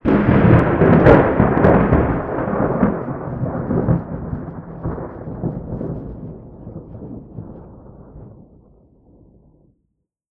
tenkoku_thunder_close02.wav